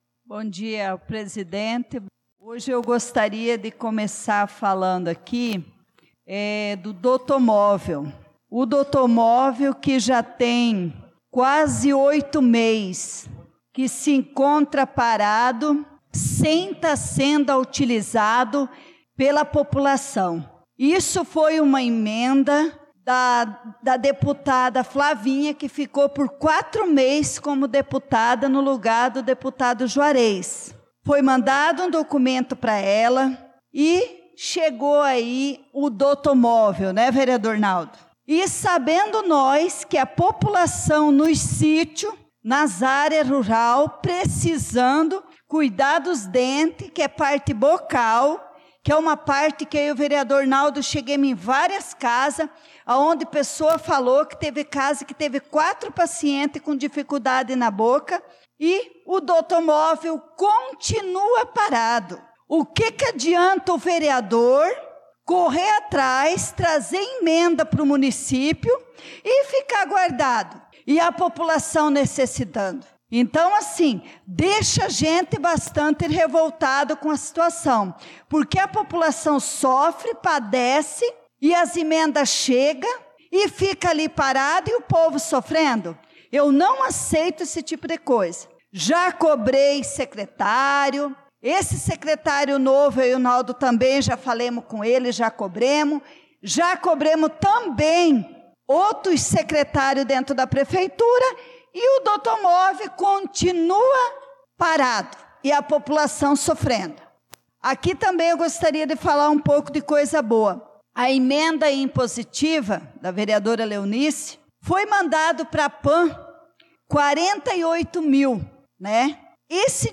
Pronunciamento da vereadora Leonice Klaus na Sessão Ordinária do dia 11/03/2025